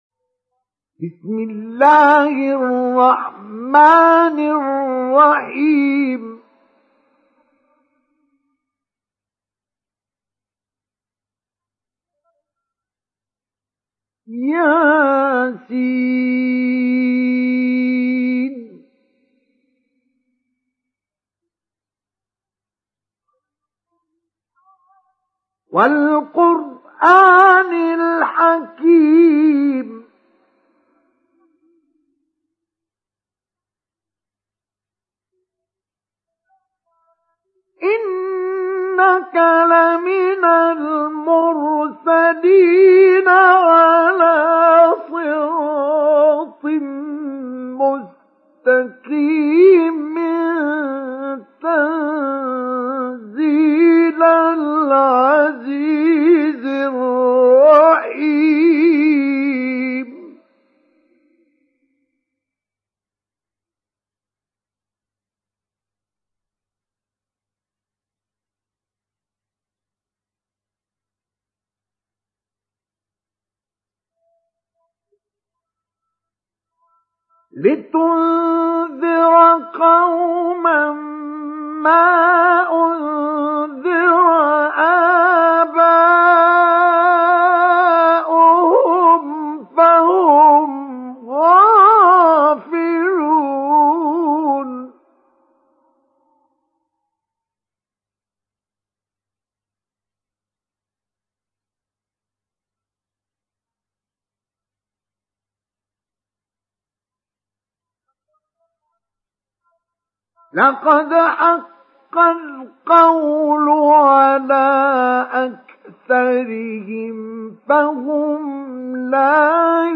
Surat Yasin Download mp3 Mustafa Ismail Mujawwad Riwayat Hafs dari Asim, Download Quran dan mendengarkan mp3 tautan langsung penuh
Download Surat Yasin Mustafa Ismail Mujawwad